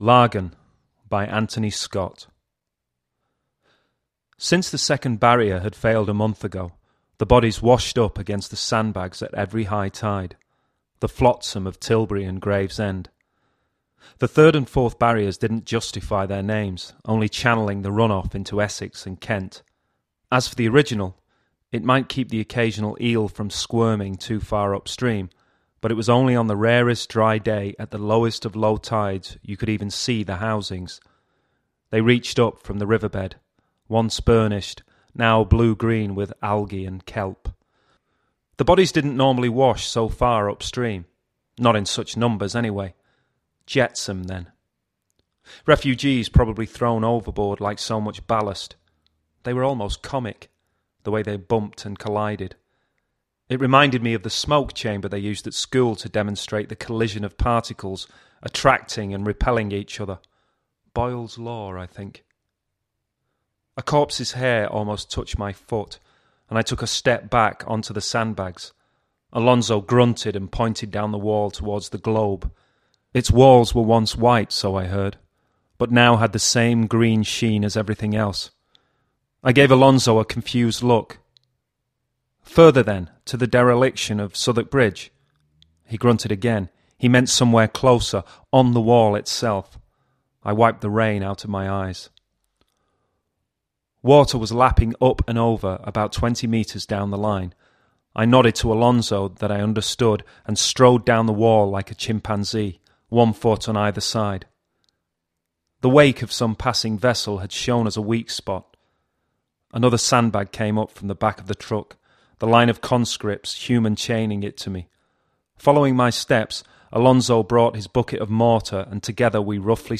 Six stories were  selected from the entries by a panel of judges (including writer Jeff Noon and Dominique Gonzalez-Foerster) to form an audiobook voiced by Christopher Eccleston.
Listen to Lagan read by Christopher Eccleston [mp3 download 4.4MB]